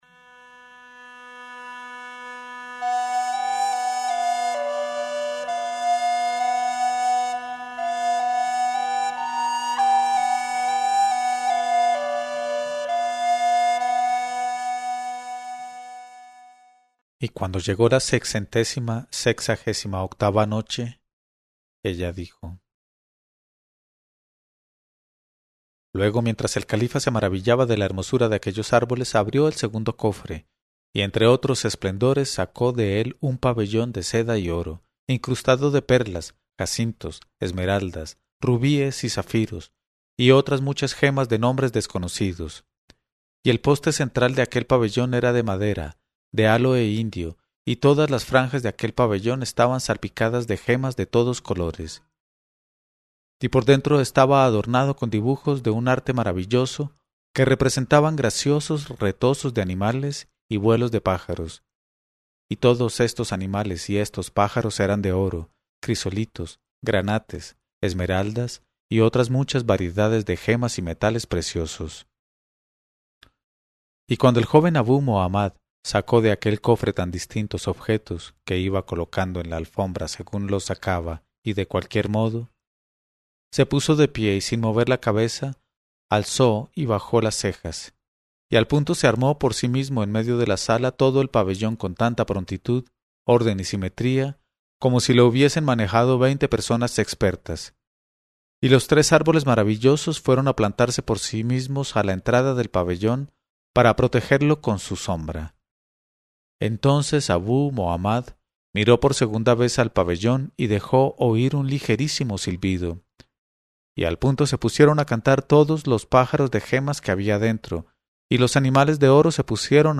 Una lectura en voz alta de Las mil noches y una noche.